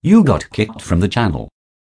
channel.kicked.wav